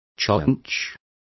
Complete with pronunciation of the translation of chaffinch.